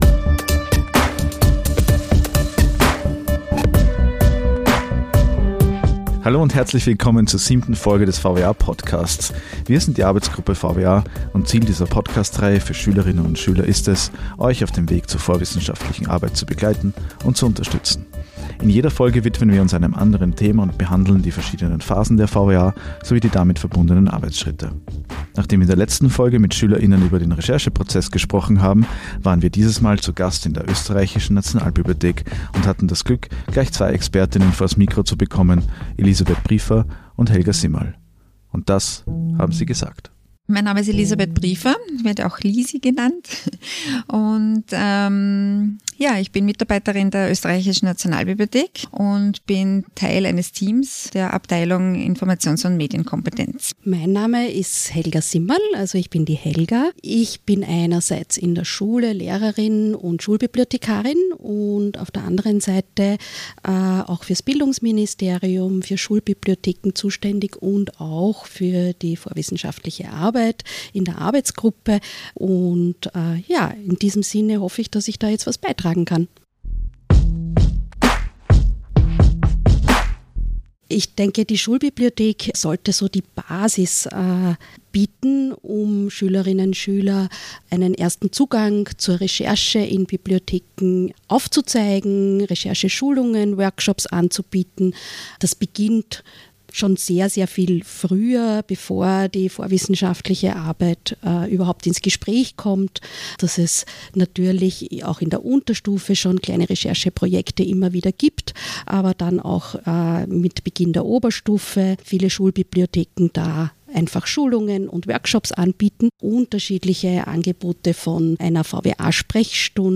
Recherche (Gespräch mit zwei Expertinnen)